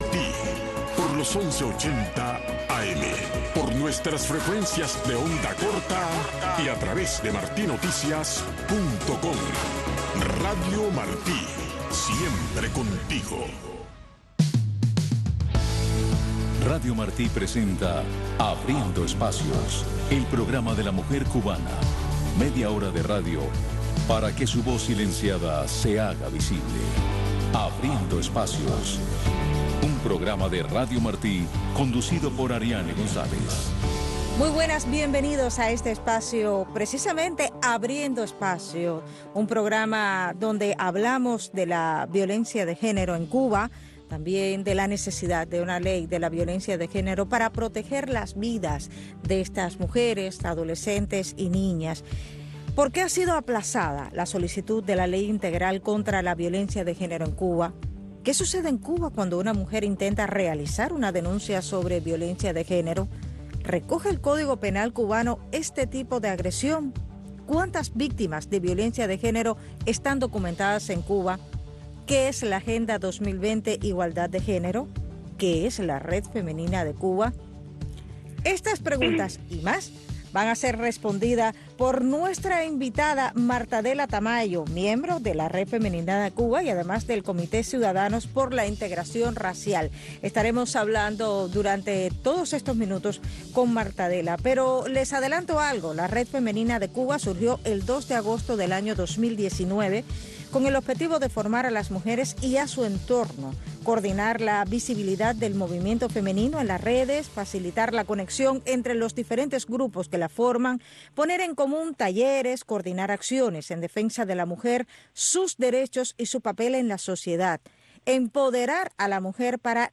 Lawton Libre es el programa conducido por el Doctor Oscar Elías Biscet que te habla de los derechos humanos, de las libertades básicas y de cómo lograr la libertad, tu libertad, porque si aprendes a ser libre todos los seremos Todos los sábados a las 7 am y también los sábados y domingos a las 11 de la noche en Radio Martí.